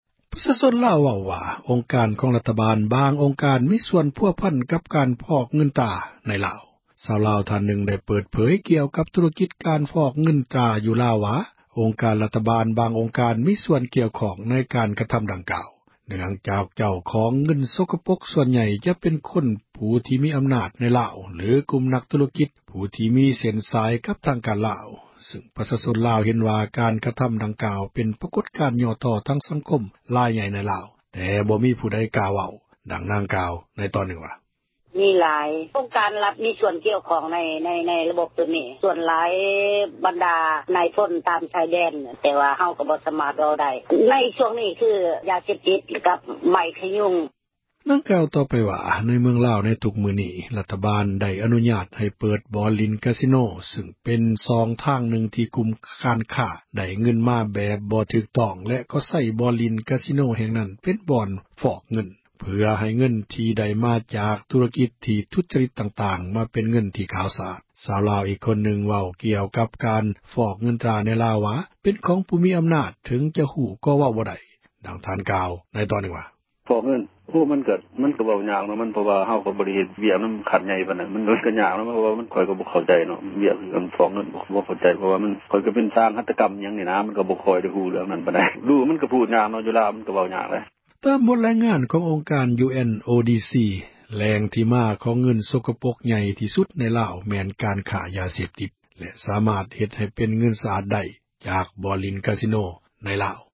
ຄົນລາວຜູ້ນັ້ນ ເວົ້າວ່າ: